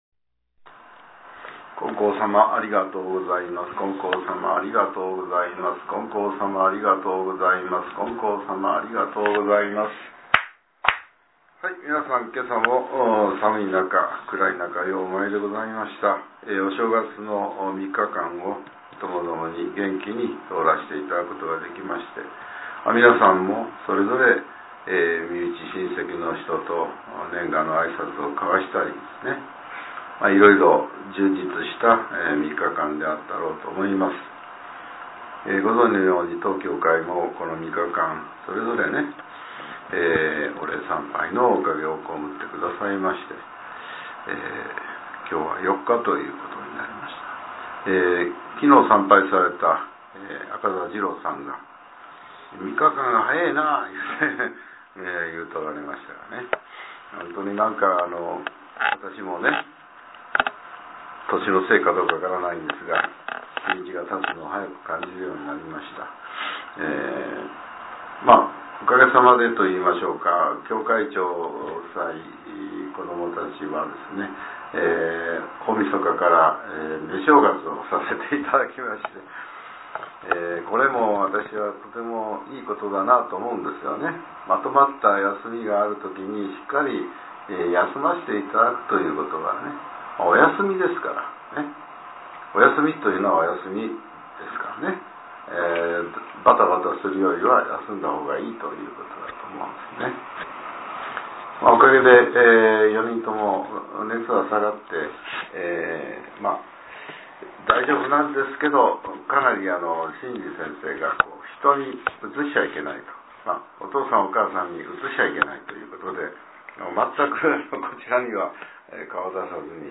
令和７年１月４日（朝）のお話が、音声ブログとして更新されています。